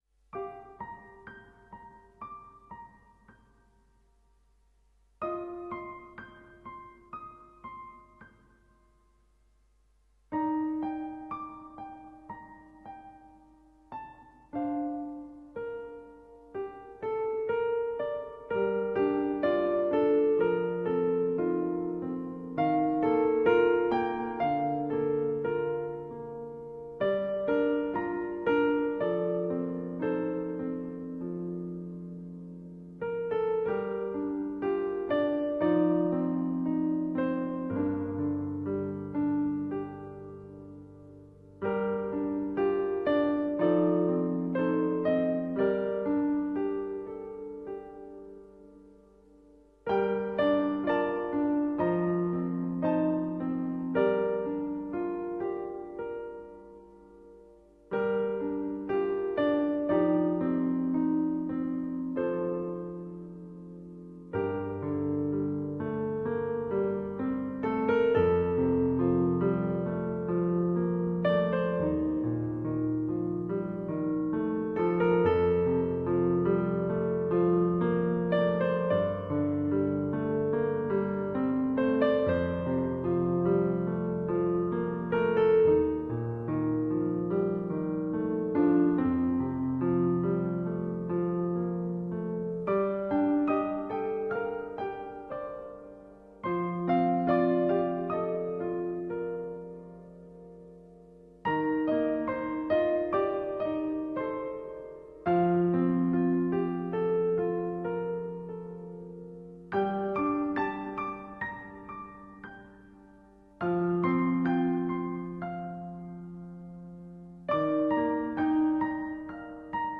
a 1 hour album of relaxing solo piano music.